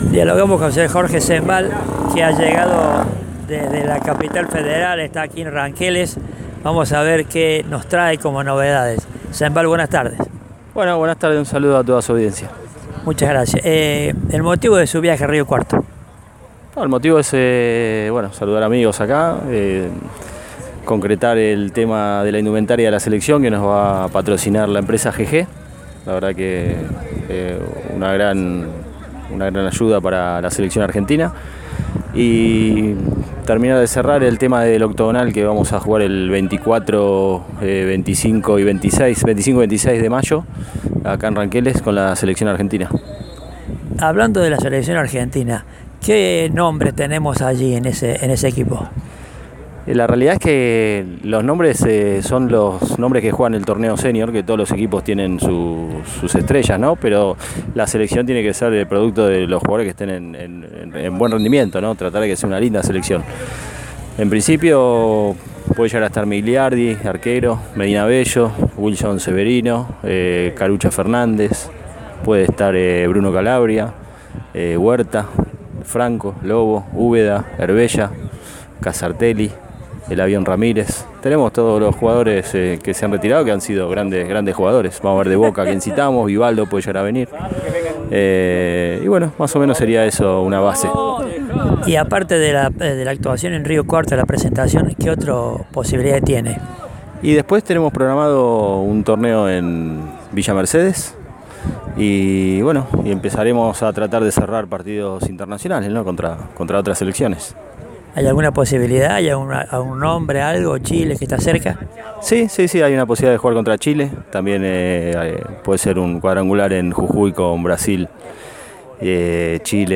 Mientras recorría las canchas de la Agrupación Ranqueles, donde se jugarán los partidos, dialogó con Deportiva para comentar los detalles de su visita, así como de la actualidad y futuro de la Selección: